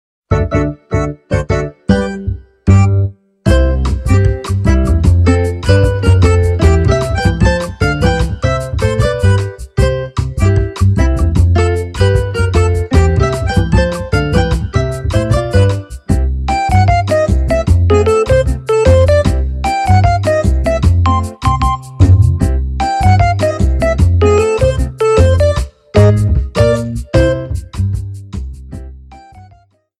Reduced length to 30 seconds, with fadeout.